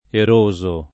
erodere [ er 1 dere ]